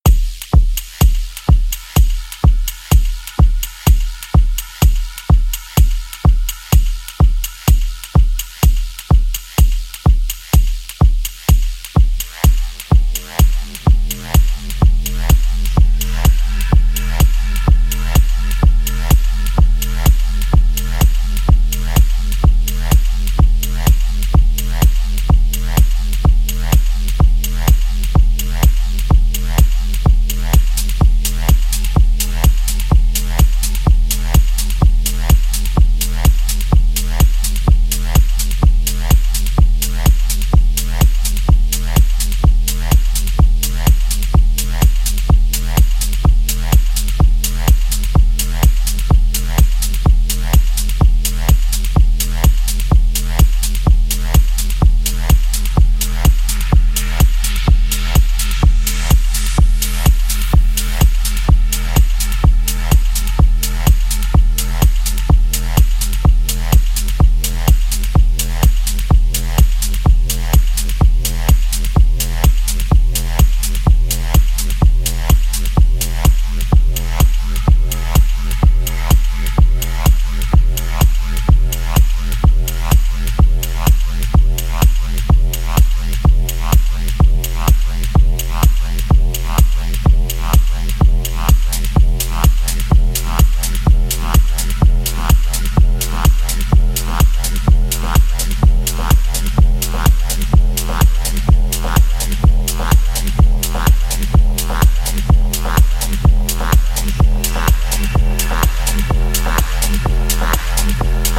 Four storming cuts of techno.
Techno